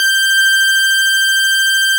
snes_synth_079.wav